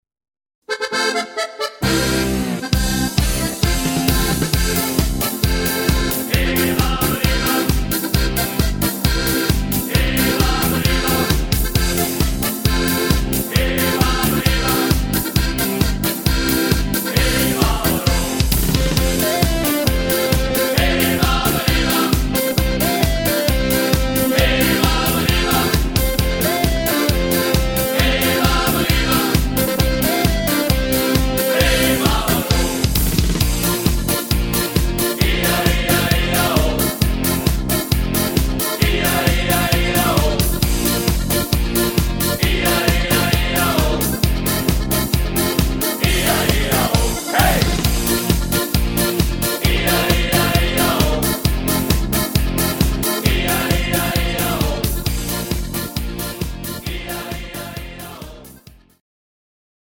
Volkstümlich & fetzig